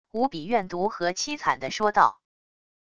无比怨毒和凄惨的说道wav音频